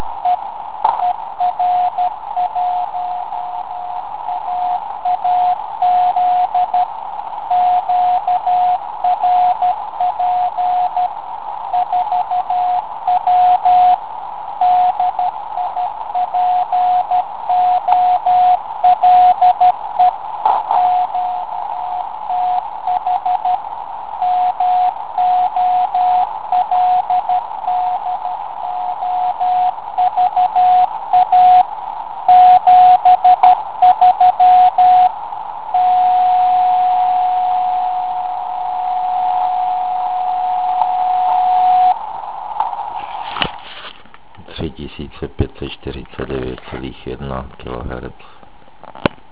Dnes kolem půl druhé, jsem např. zaslechl na 3549.1 KHz ER1AAZ